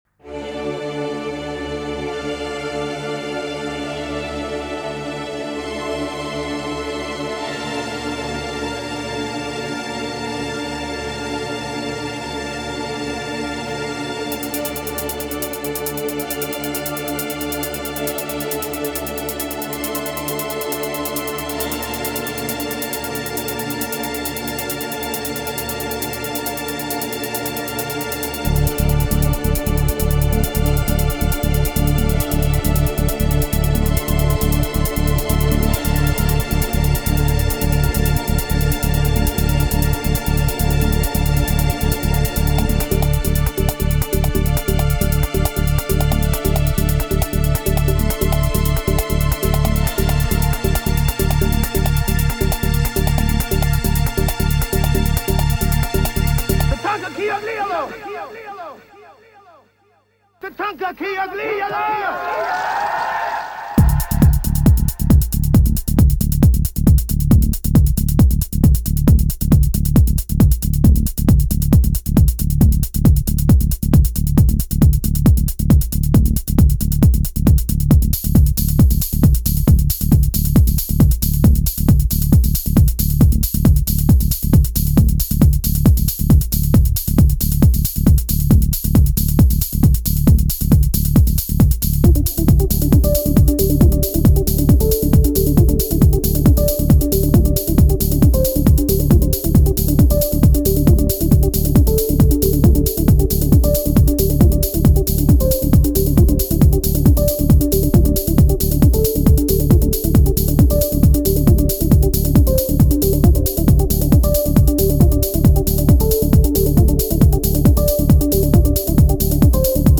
Genre: Trance.